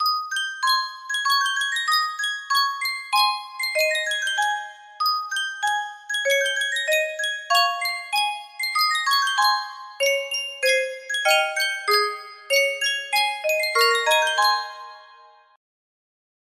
Yunsheng Music Box - Once in Royal David's City 5923 music box melody
Full range 60